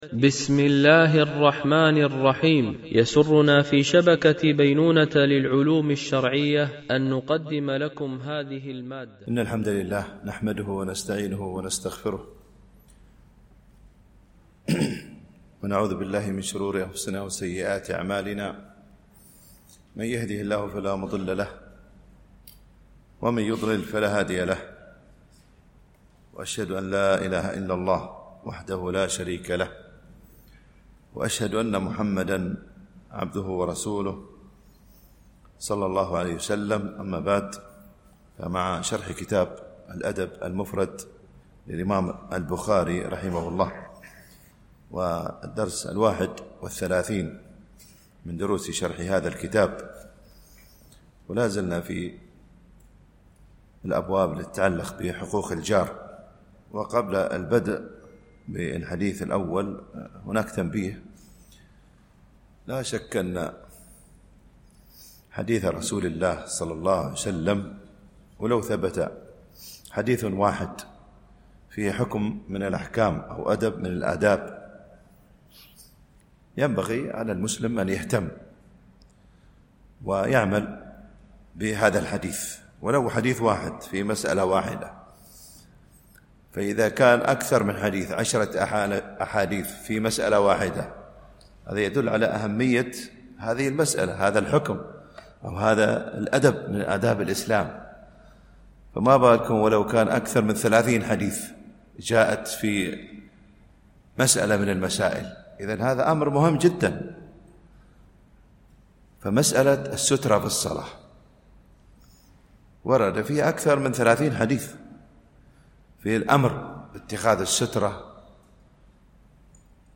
MP3 Mono 44kHz 64Kbps (CBR)